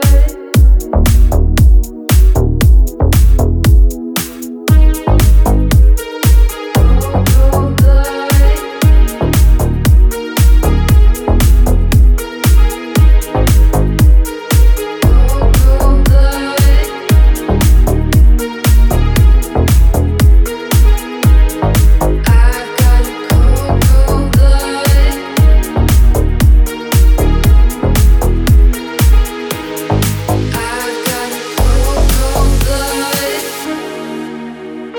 # Downtempo